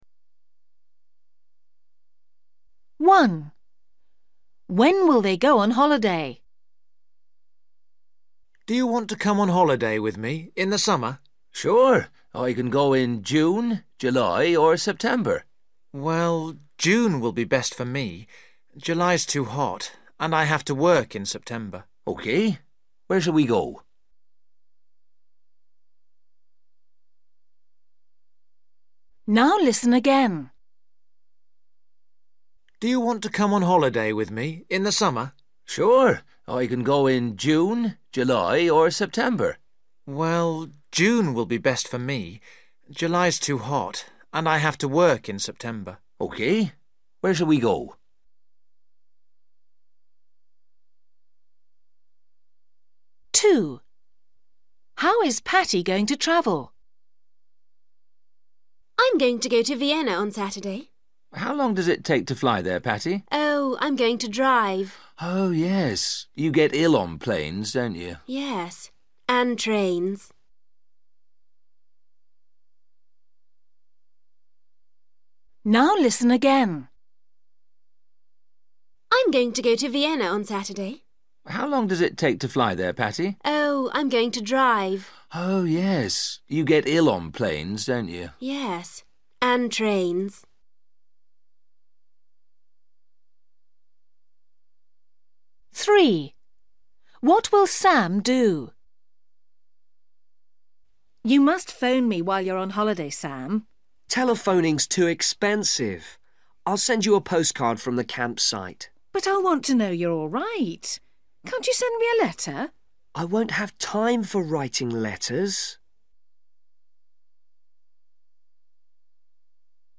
You will hear five short conversations.
You will hear each conversation twice.